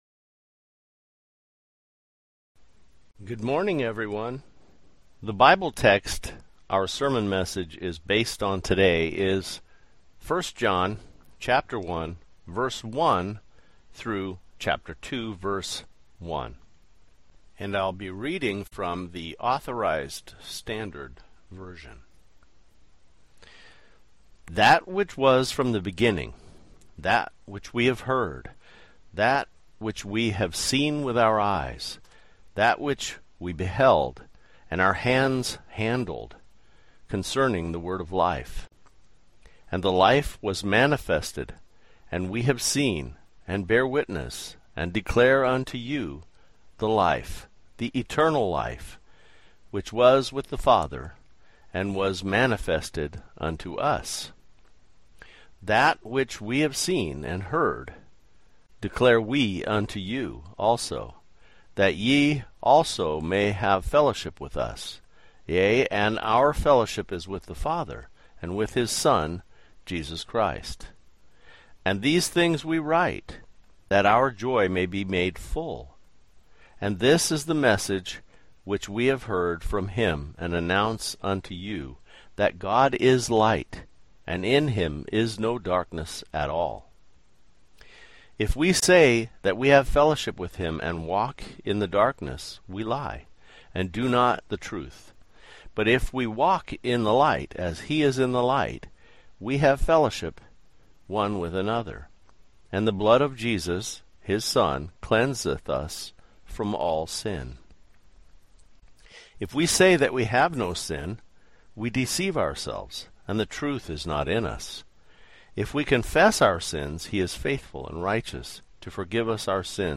Grace Communion Port Orchard's sermon message for the 2nd Sunday of the Easter Season.